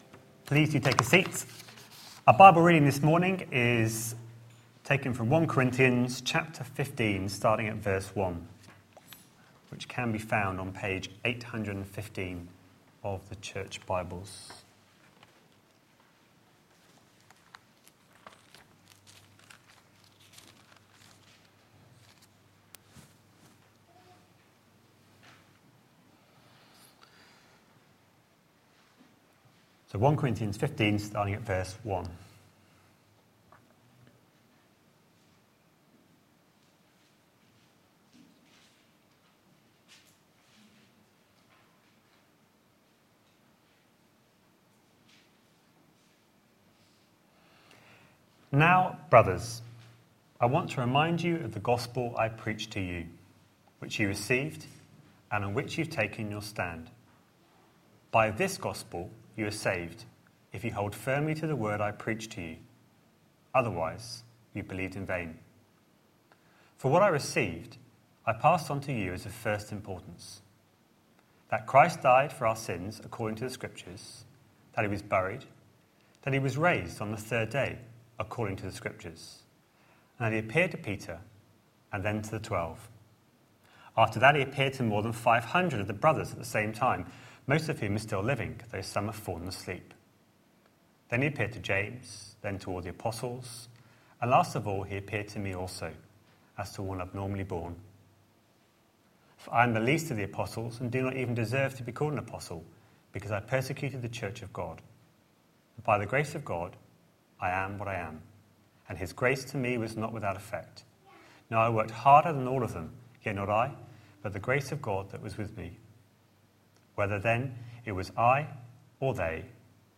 A sermon preached on 25th January, 2015, as part of our Work series.